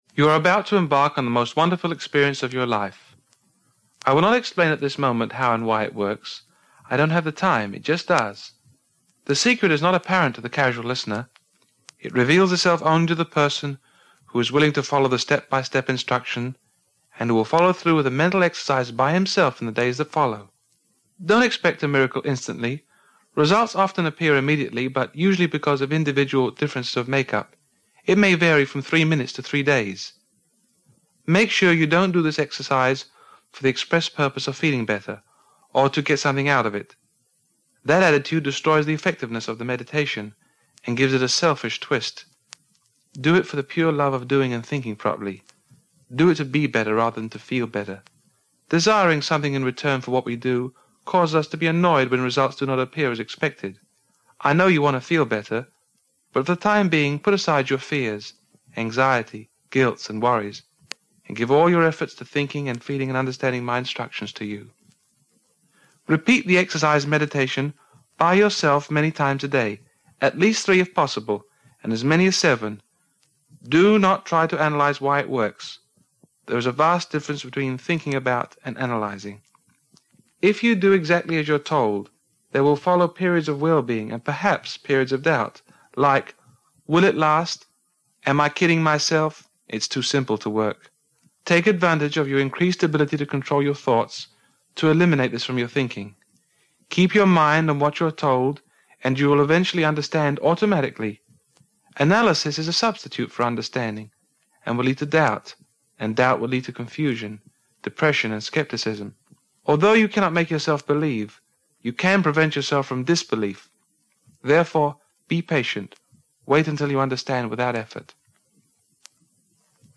A meditation that I did years ago...